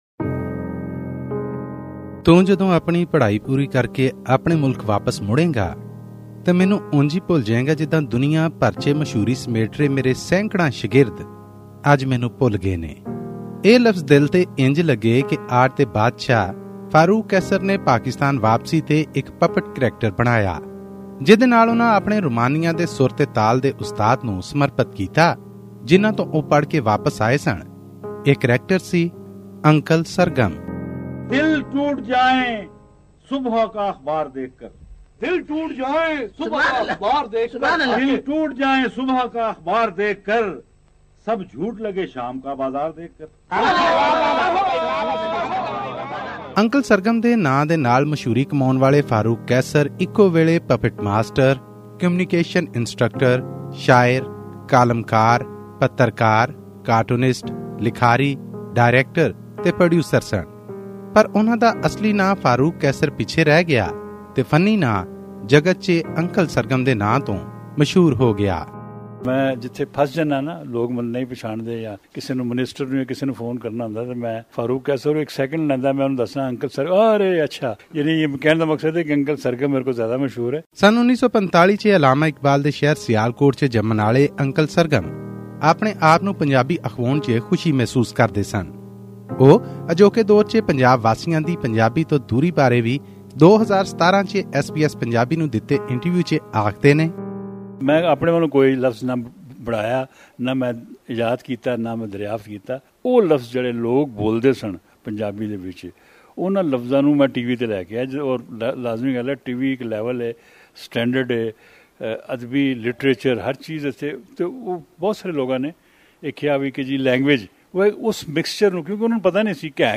special_report_on_the_death_of_uncal_sargam_farooq_qaisar.mp3